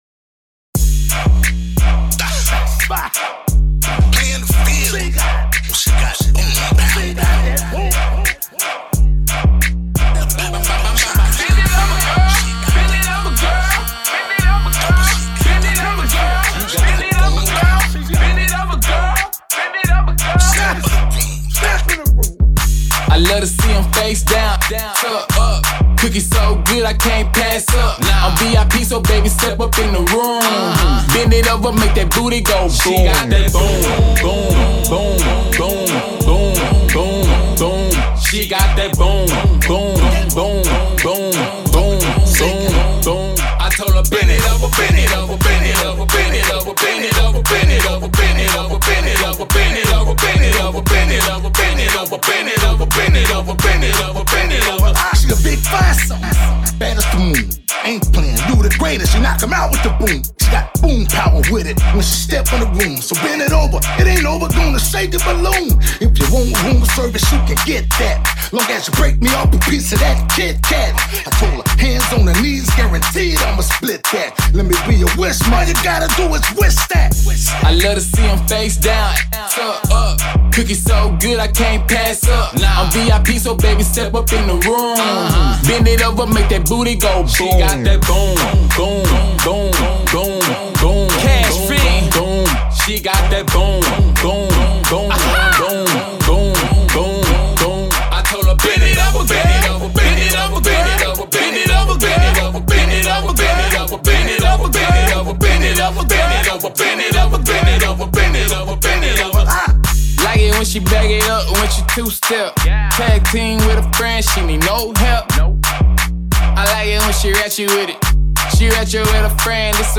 Hiphop
a club anthem
Hot banger here